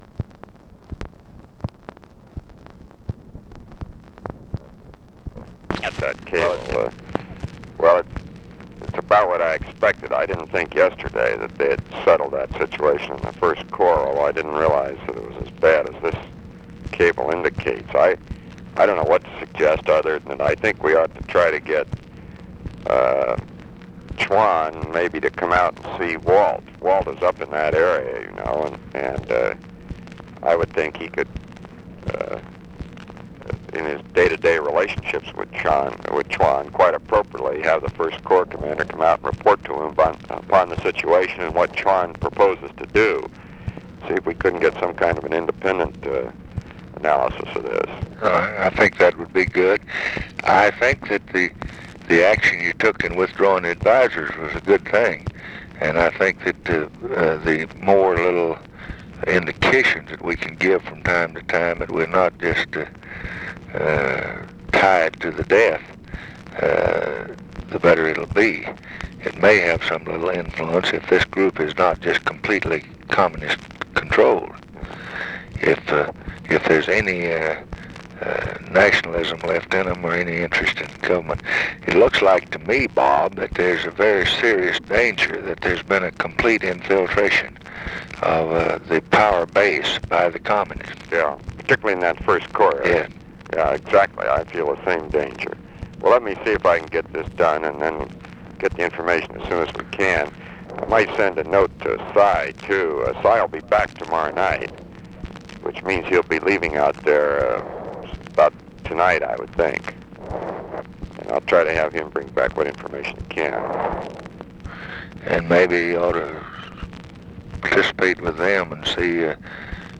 Conversation with ROBERT MCNAMARA, April 7, 1966
Secret White House Tapes